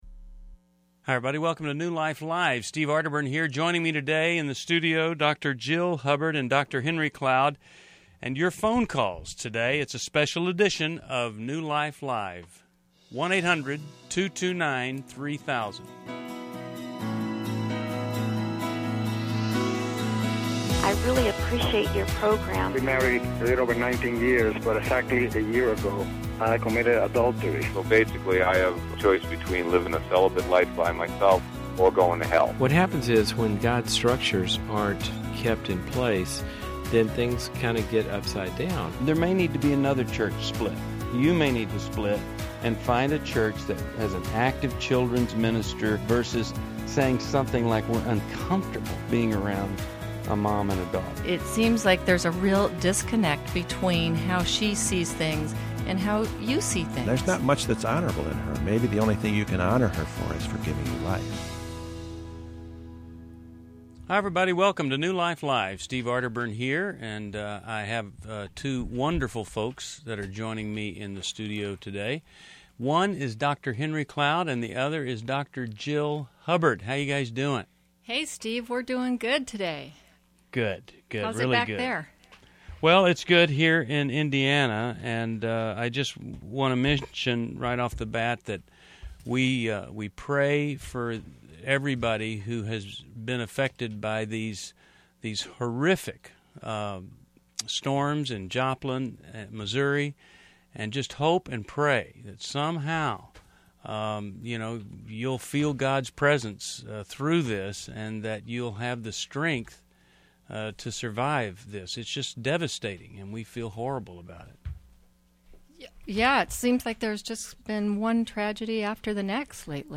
Support New Life's mission in this special episode featuring listener testimonials.